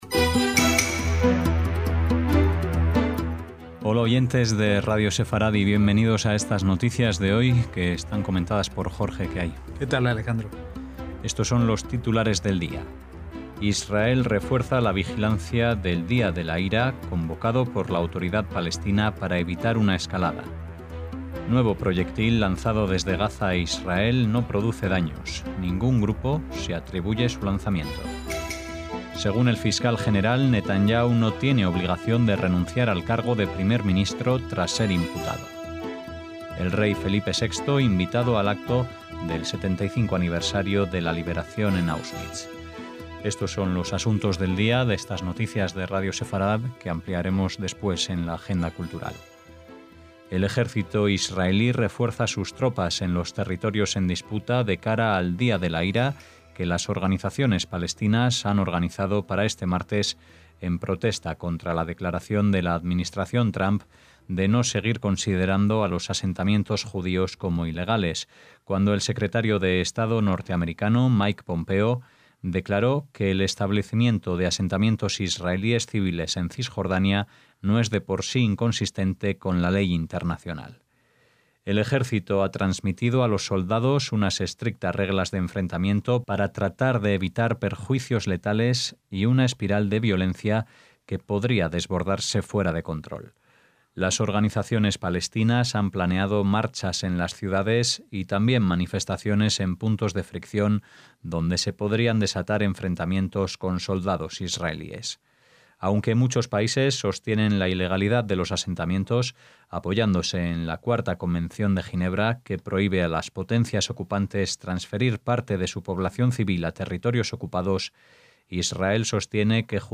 NOTICIAS - Titulares de hoy: Israel refuerza la vigilancia del Día de la Ira convocado por la Autoridad Palestina para evitar una escalada.